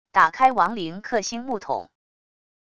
打开亡灵克星木桶wav音频